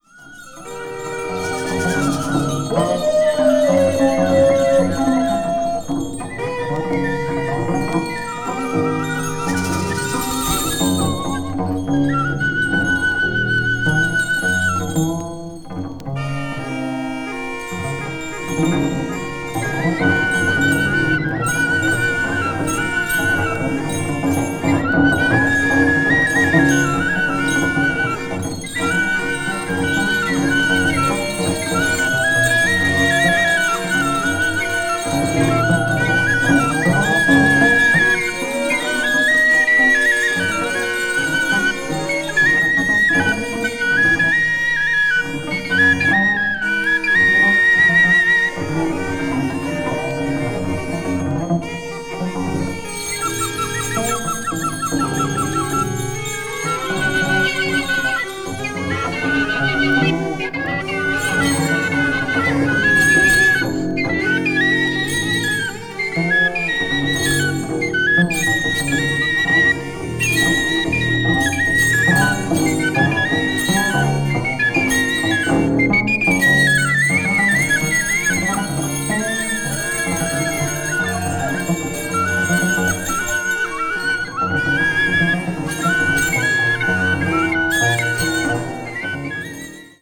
ウッド・フルート